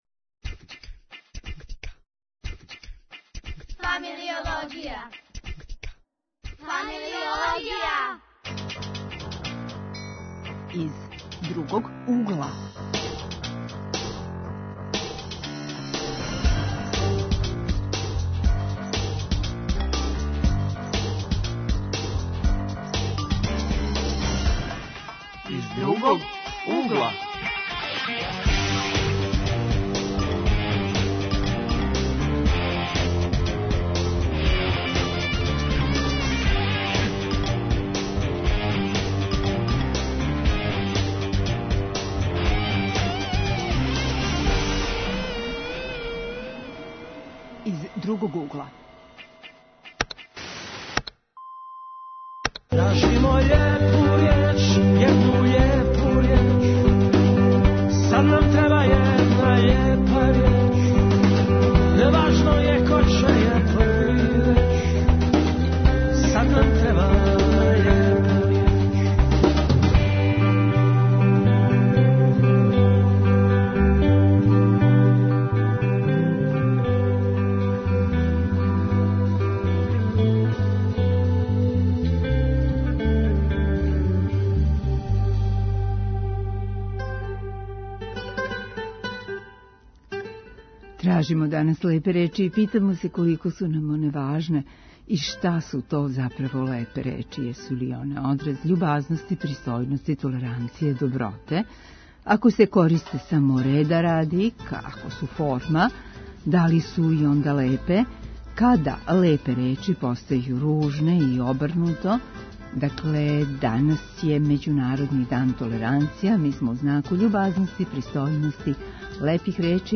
13:05 -> 22:50 Извор: Радио Београд 1 Аутор